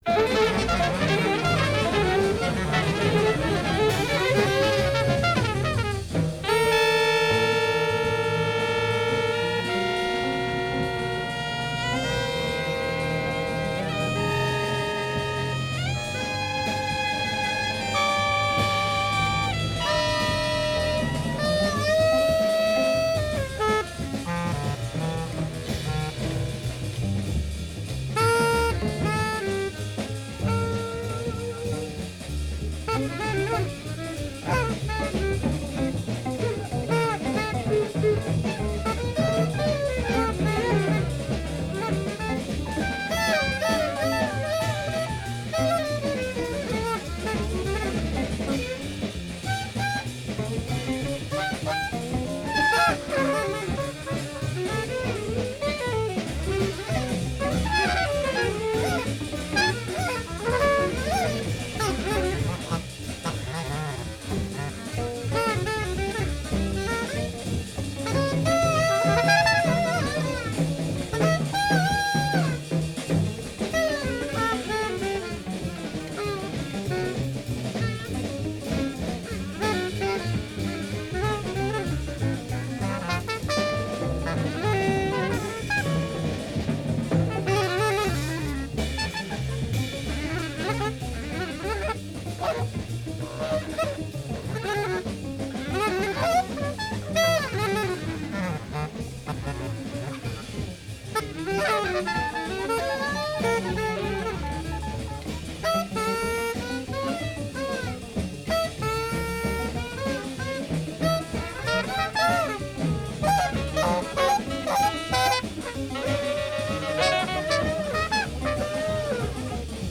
trumpet
alto saxophone
bass clarinet
double bass